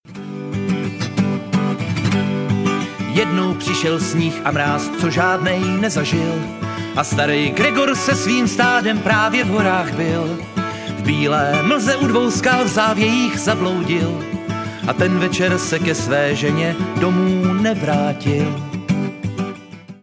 Irská tradiční hudba s folkovými prvky
Nahráno a smícháno: 2016 ve studiu Klíč